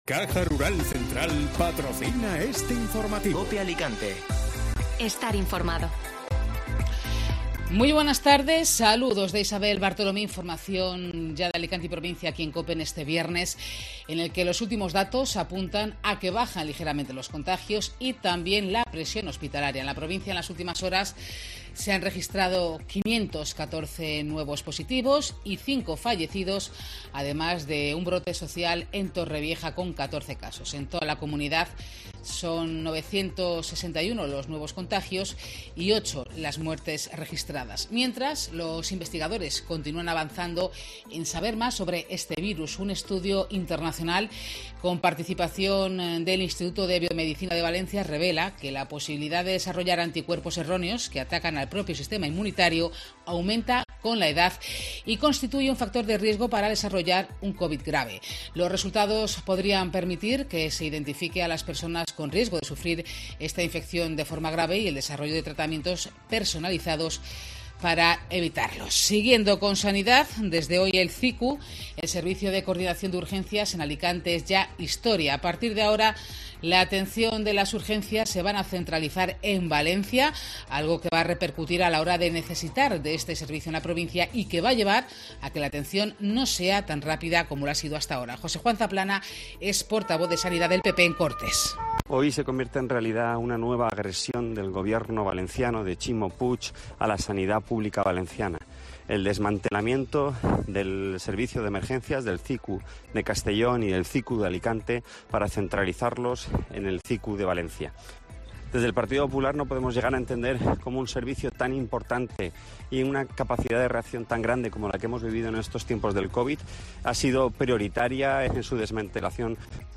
Informativo Mediodía COPE (Viernes 20 de agosto)